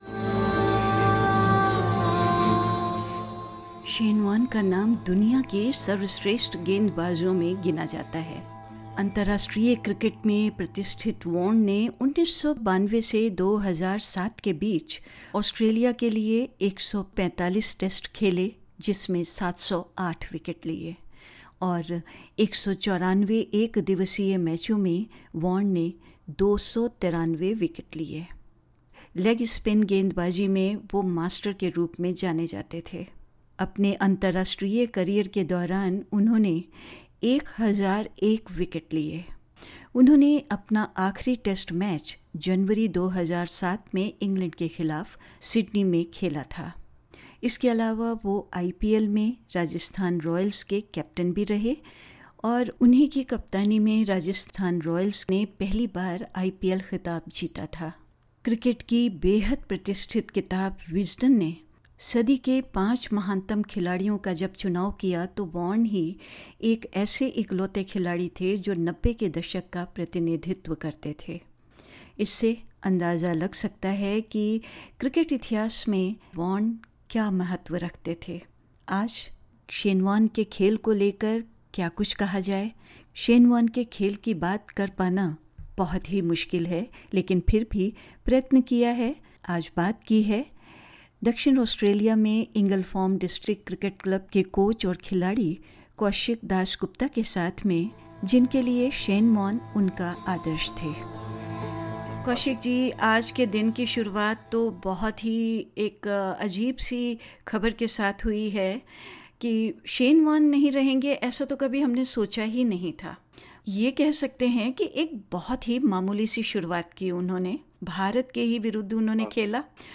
‘शेन वार्न एक चैम्पियन थे’, भावुक क्रिकेट कोच ने किया याद